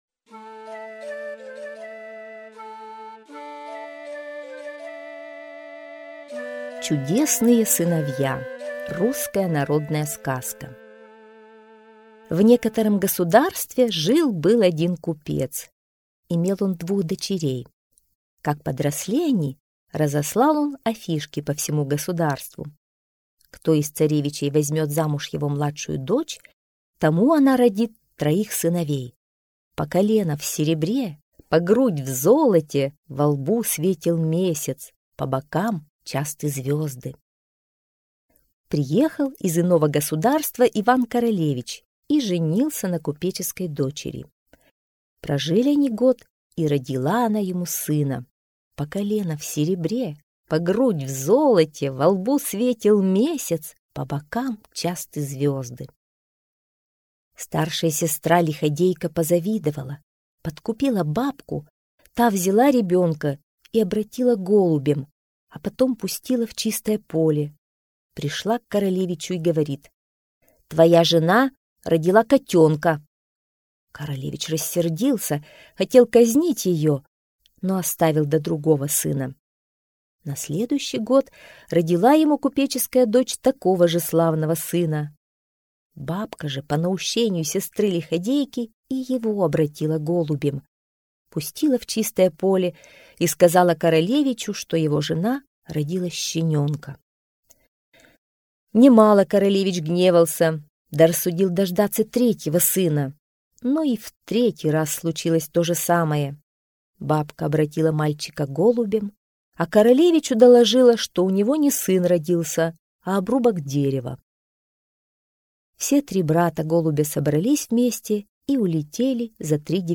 Чудесные сыновья — русская народная аудиосказка.